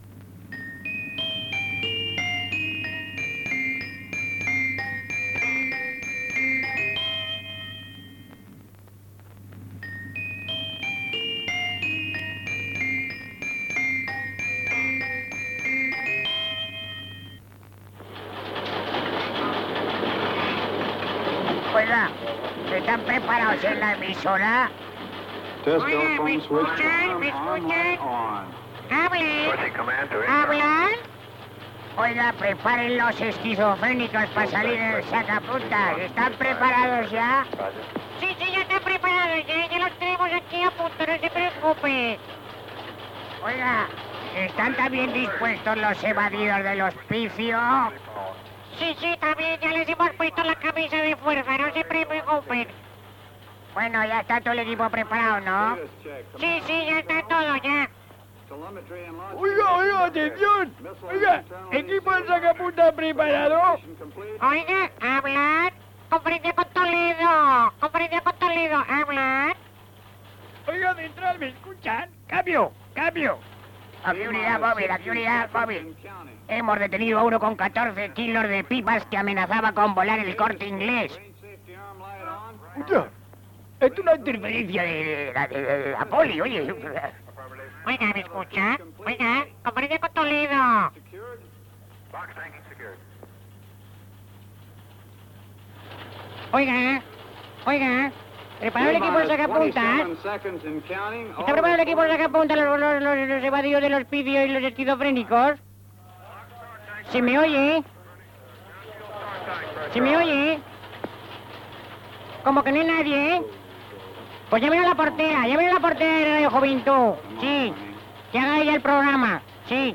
Sintonia de Radio Juventud, l'equip ja està preparat per sortir en antena, presentació i esment a que part de l'equip està de vacances, tema musical, trucades de l'audiència intentant endivinar la cançó que sona
Entreteniment
FM